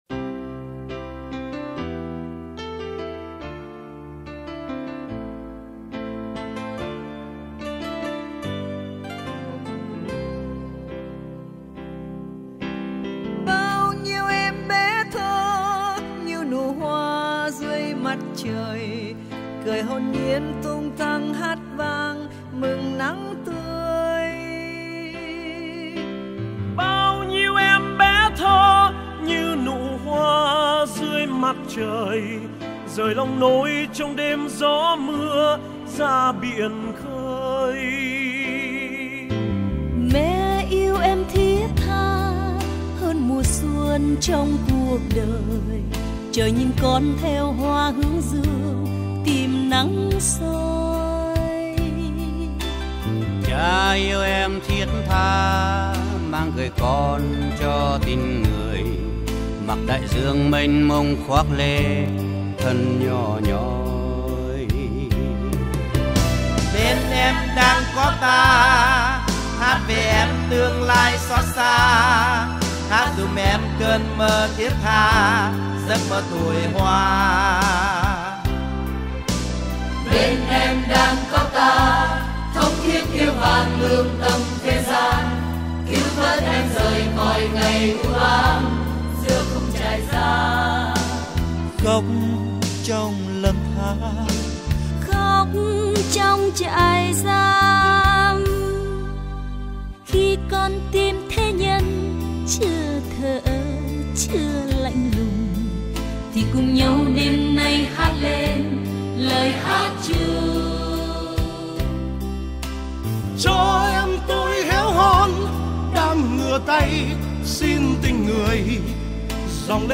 80 nghệ sĩ cùng hợp ca